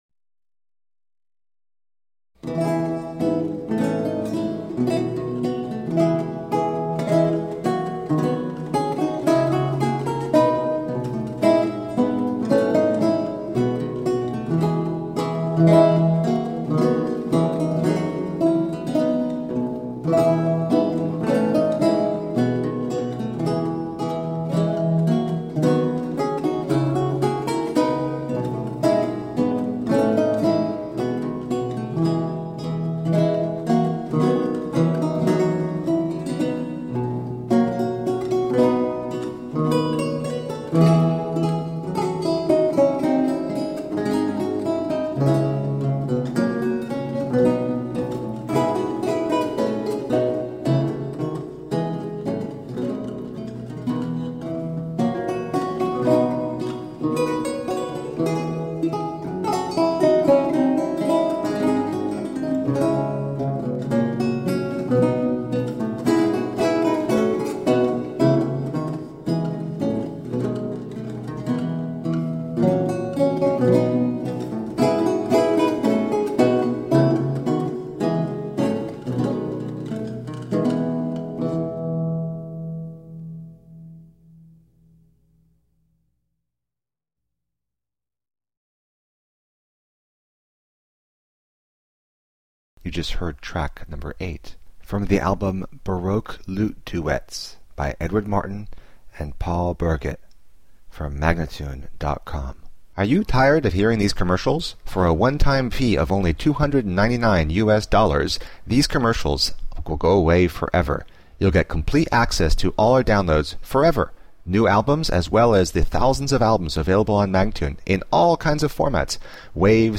Vihuela, renaissance and baroque lute.